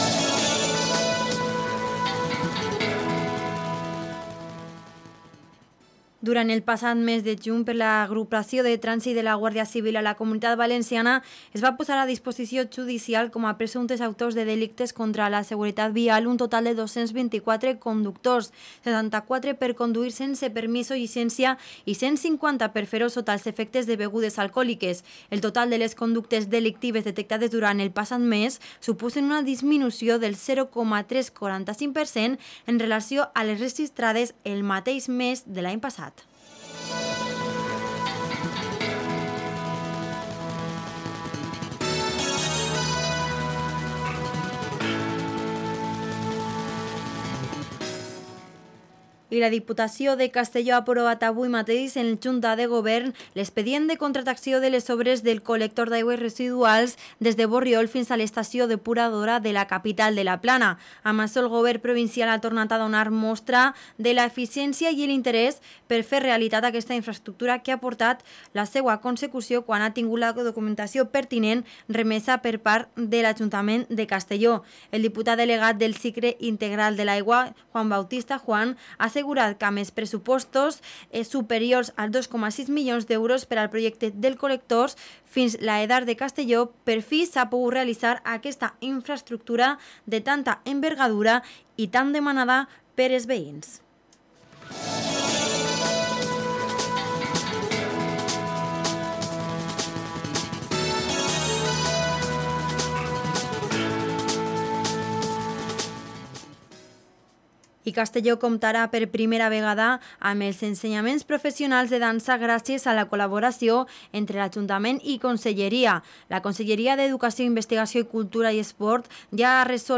Morella y su sexenni también han sido protagonsitas de hoy; hemos entrevistado al alcalde de la localidad, Rhamsés Ripollés, sobre los festejos que nos esperan en agosto.